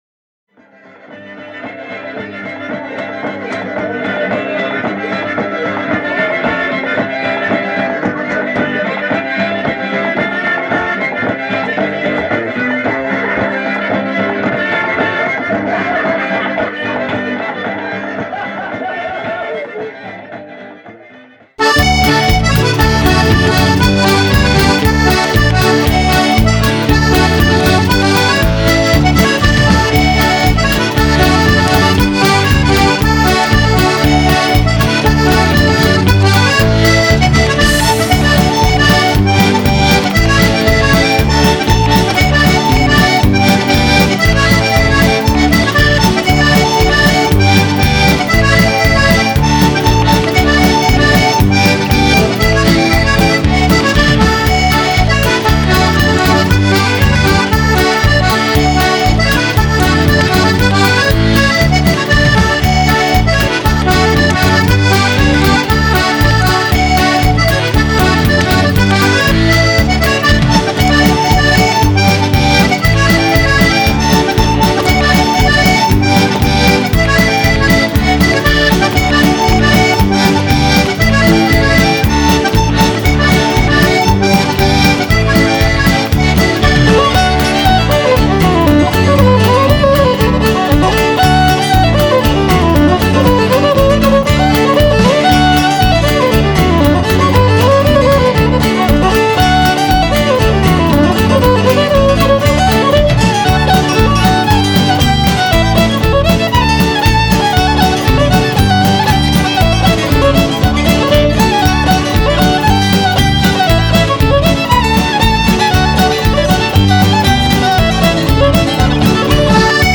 lively album of reels and waltzes
foot-stompin’, feel-good music
fiddle